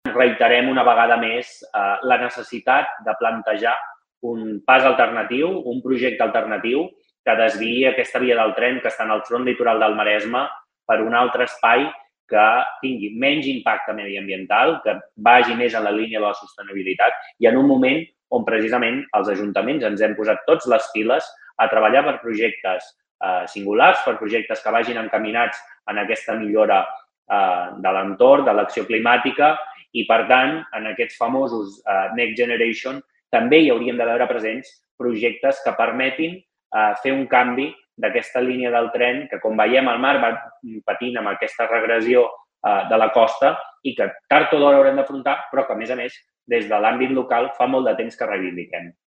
L’alcalde de Malgrat de Mar, Jofre Serret, ha reclamat l’impuls del projecte de línia ferroviària per l’interior. Són declaracions a la xarxa.